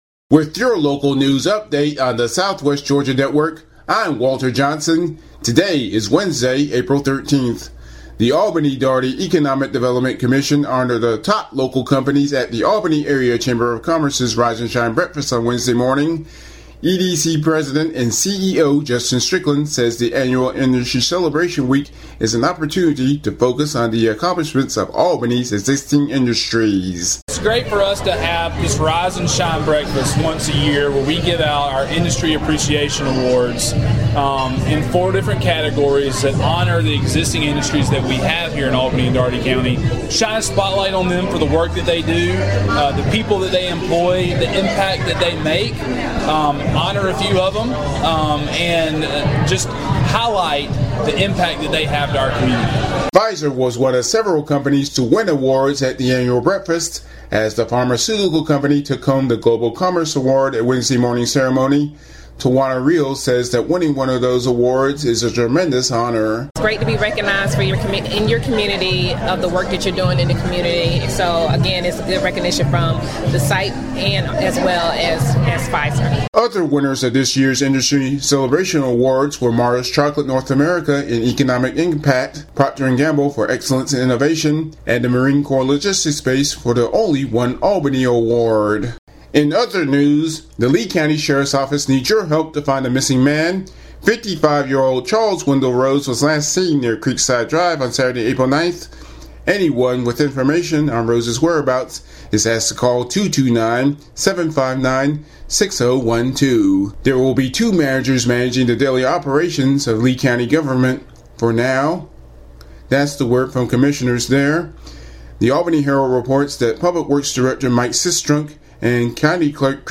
Audio News Updates (Pilot)